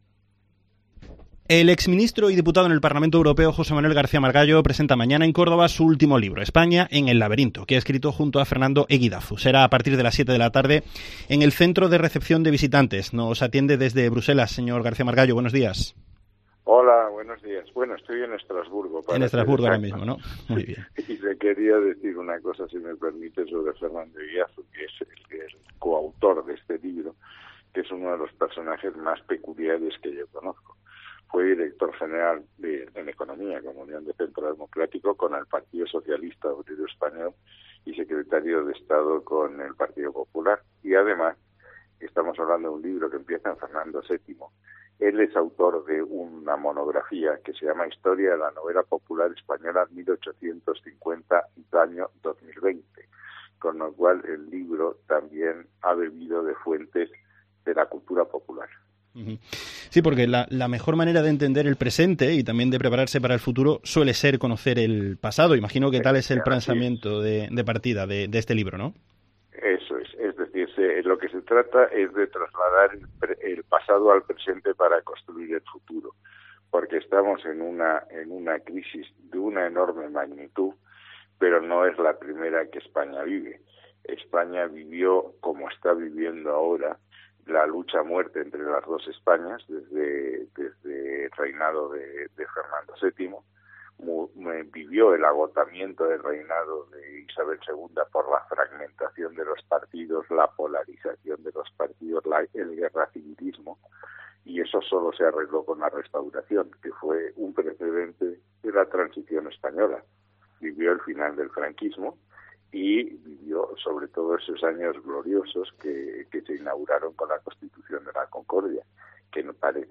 Entrevista a José Manuel García-Margallo en COPE Córdoba